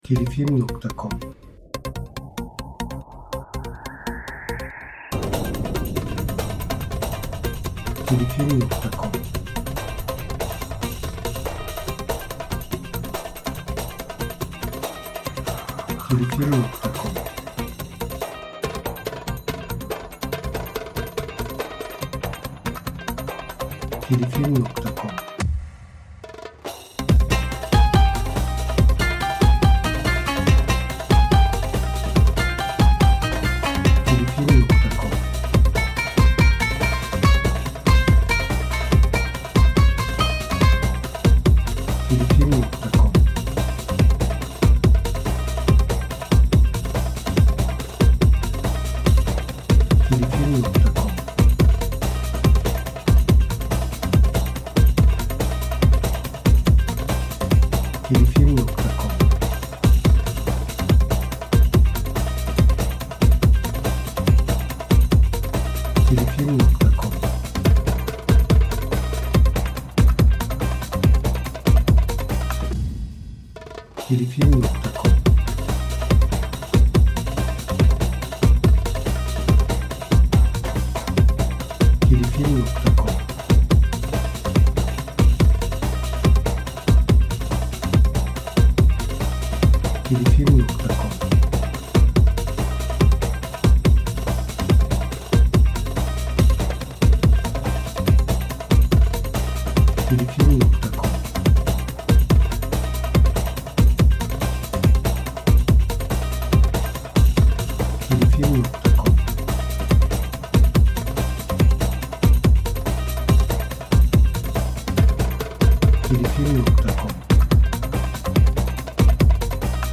Eser Türü : Müzikal Tema Eser Tipi : Enstrümental